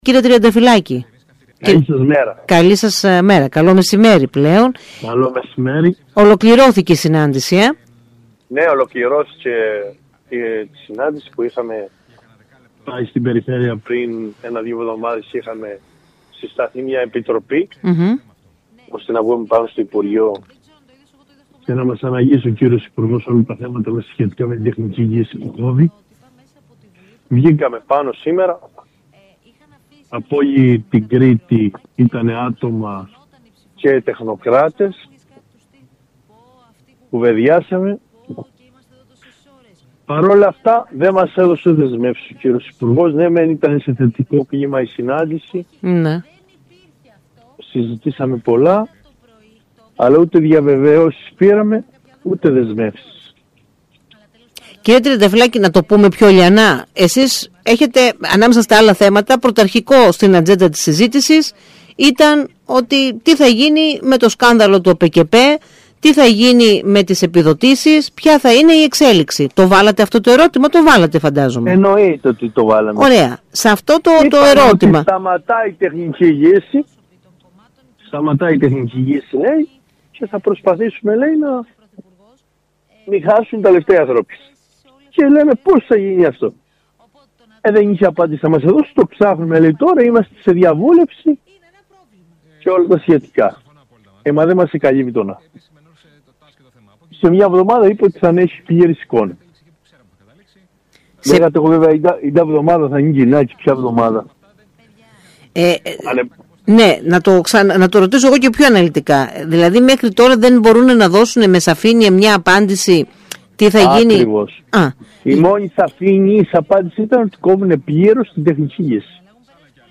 «Το κλίμα ήταν θετικό αλλά δεν πήραμε δεσμεύσεις και διαβεβαιώσεις», ανέφερε μιλώντας στον ΣΚΑΪ Κρήτης 92.1